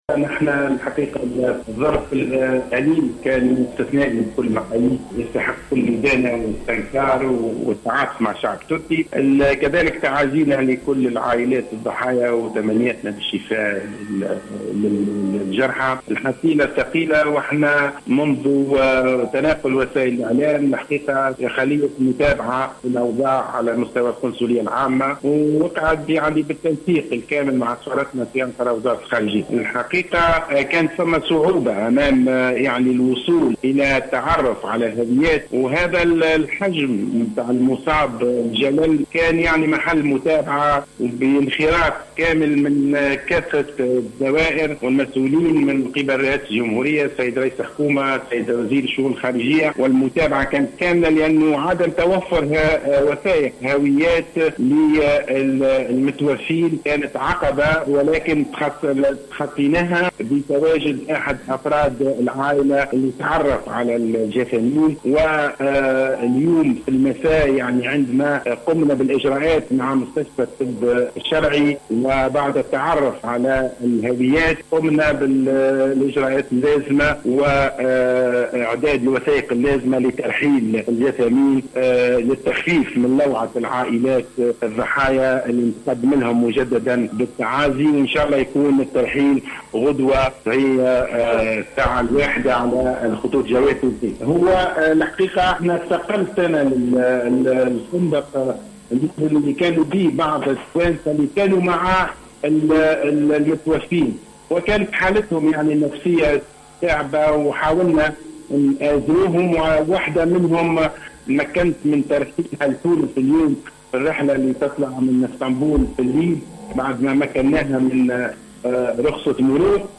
Le consul général de la Tunisie à Istanbul, Fayçal Ben Mustapha a affirmé, lors d'une déclaration accordée dimanche soir à la Télévision nationale, que le rapatriement des corps des deux victimes tunisiennes de l'attaque terroriste perpétrée le soir du réveillon contre une boite de nuit à Istanbul, devra se faire ce lundi 2 janvier 2017.